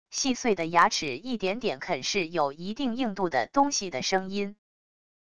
细碎的牙齿一点点啃噬有一定硬度的东西的声音··wav音频